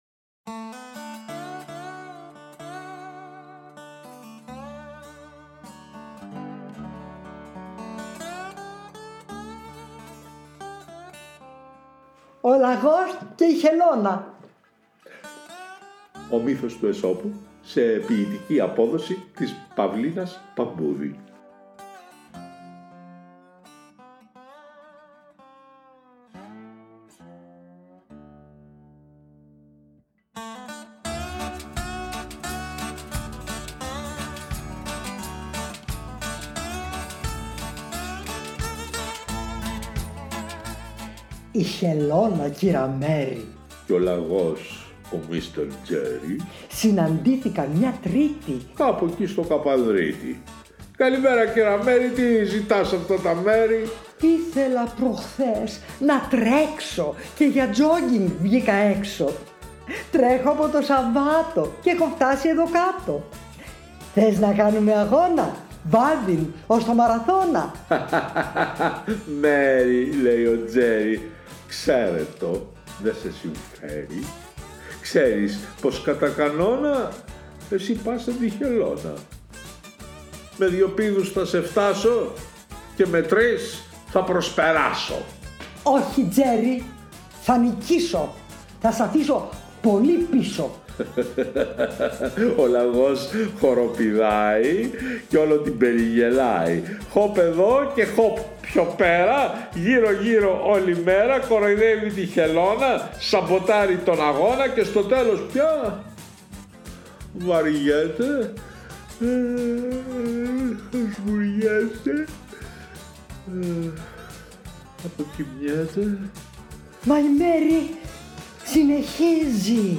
Το μουσικό κομμάτι είναι από το soundtrack του Indian Runner  [1991]
Jack Nitzsche & David Lindley – ”Goin’ To Columbus”